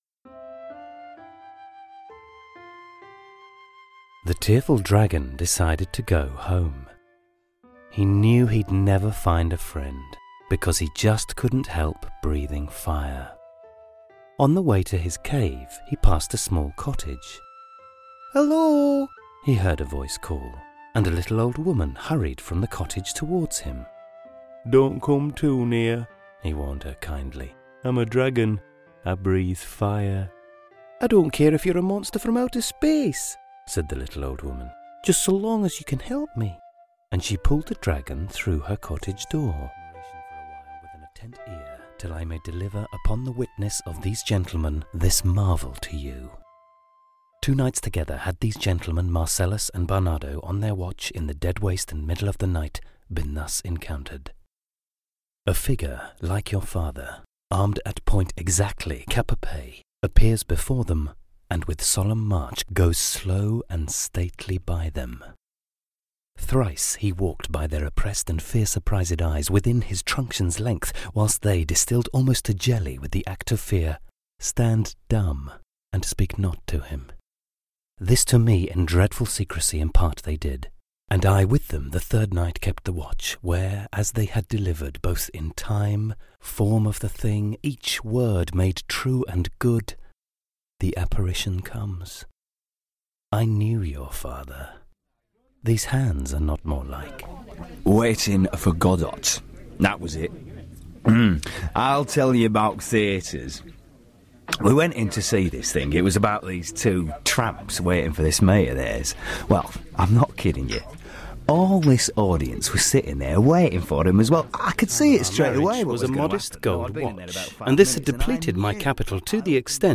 Profi-Sprecher Britisch-Englisch.
britisch
Sprechprobe: Sonstiges (Muttersprache):